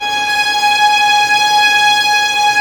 Index of /90_sSampleCDs/Roland - String Master Series/STR_Vlns Bow FX/STR_Vls Pont wh%